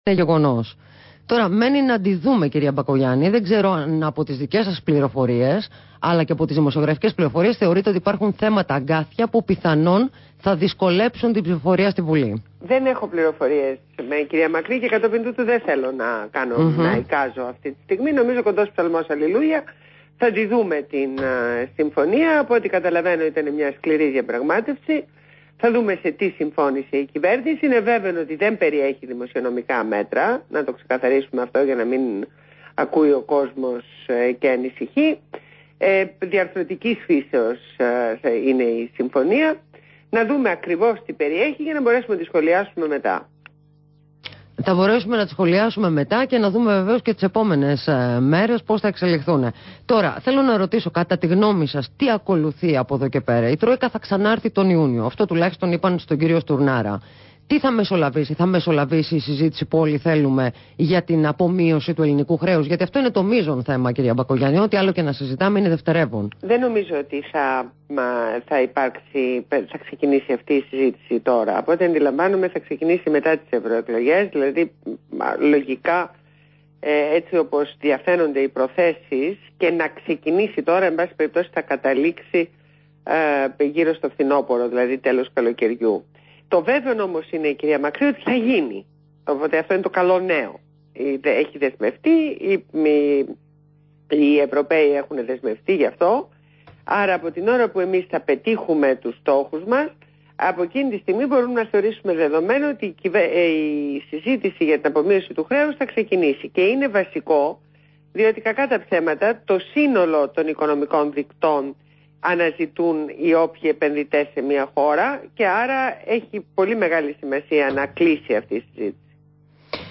Συνέντευξη στον REALfm